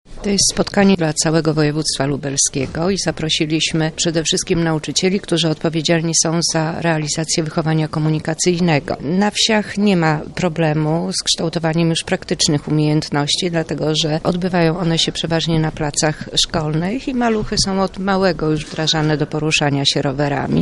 Trwa konferencja dotycząca bezpieczeństwa rowerzystów.
O szczegółach mówi Anna Szczepińska, wicekurator oświaty w Lublinie